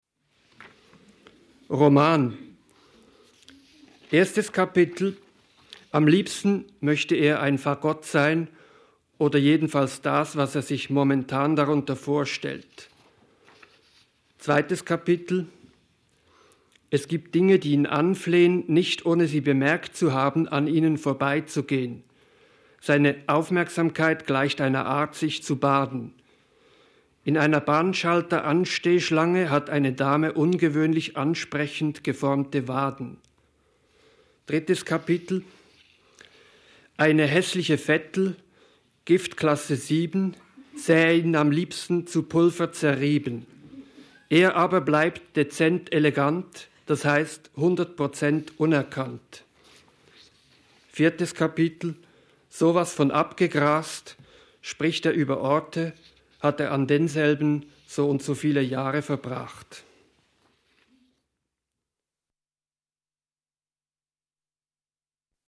Lesung
in der literaturWERKstatt Berlin zur Sommernacht der Lyrik – Gedichte von heute